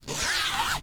ZIPPER_Long_01_mono.wav